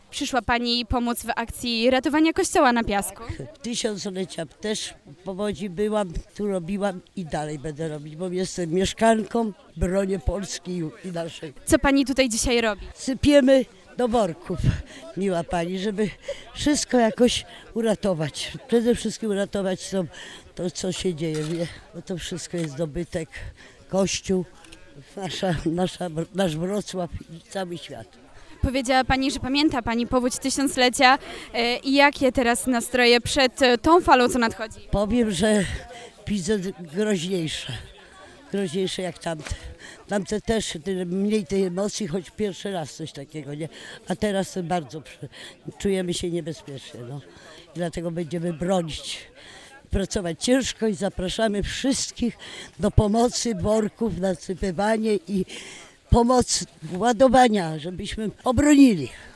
na-strone_4_starsza-pani.mp3